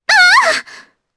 Epis-Vox_Damage_jp_01.wav